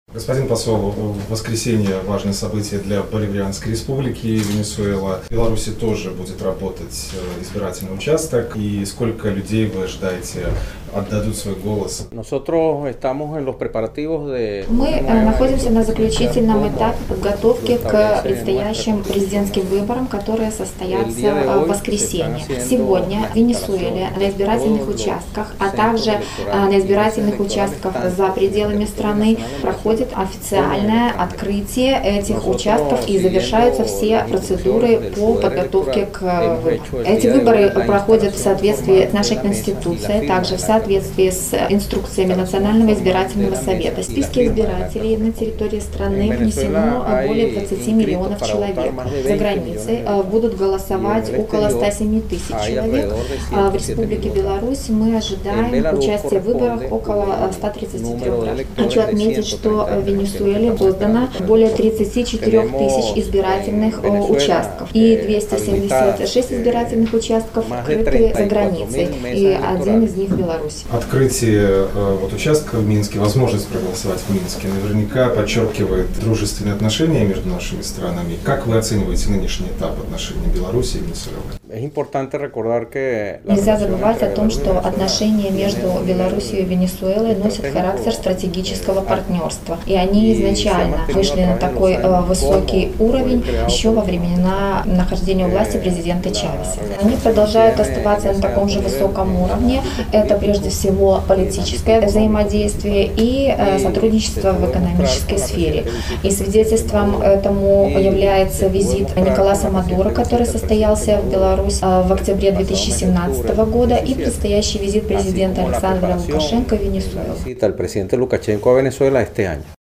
О готовности к избирательной кампании-2018 международному радио «Беларусь» рассказал Чрезвычайный и Полномочный Посол Боливарианской Республики  Венесуэла в Республике Беларусь Хосе Боггиано Периччи.
Посол Венесуэлы в Беларуси Хосе Боггиано Периччи